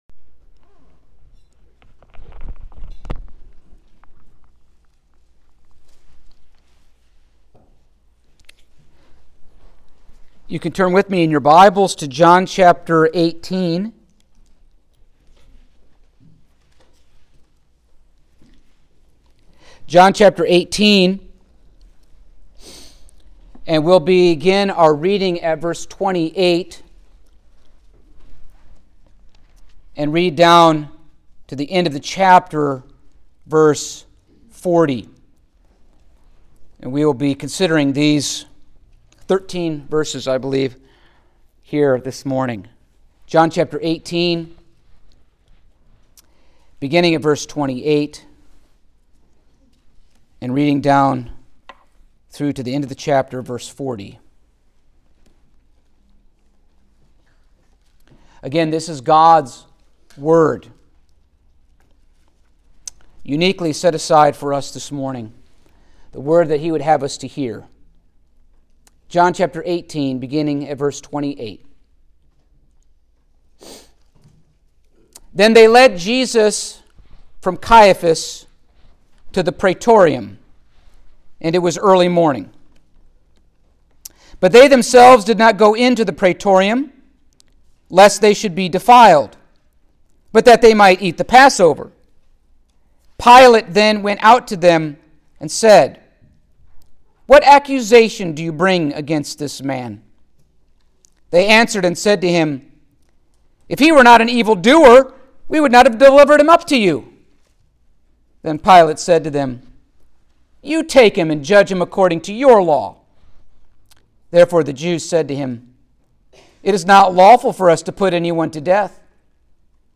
Passage: John 18:28-40 Service Type: Sunday Morning